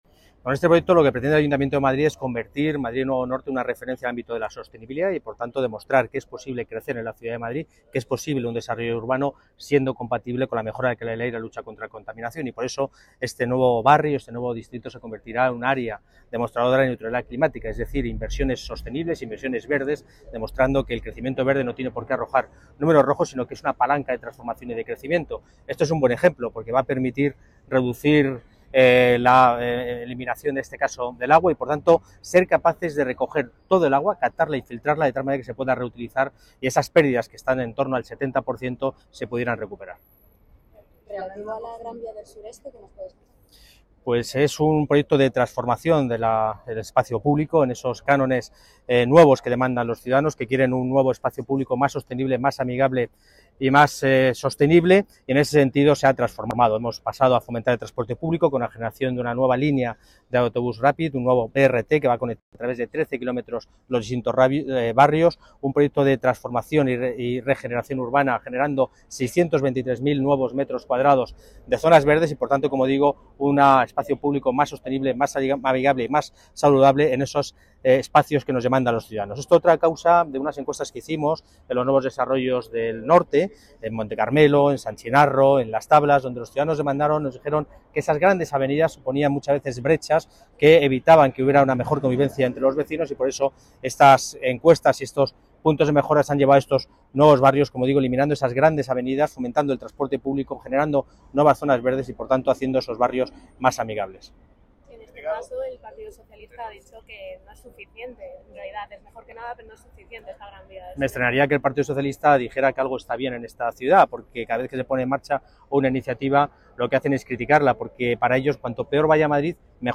Nueva ventana:Intervención de la delegada de Obras y Equipamientos, Paloma García Romero